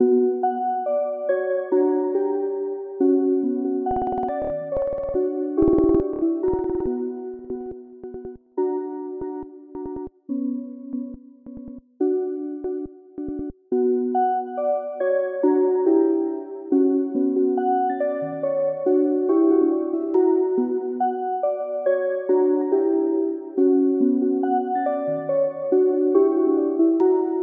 描述：浓密的
Tag: 140 bpm Trap Loops Bells Loops 4.61 MB wav Key : Unknown